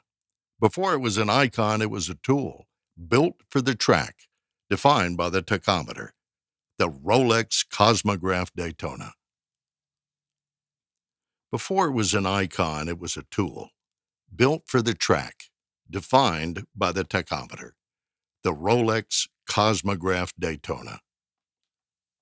Professional Voice Over Artist
Commercial for Rolex
English Neutral, English - Southern US
Senior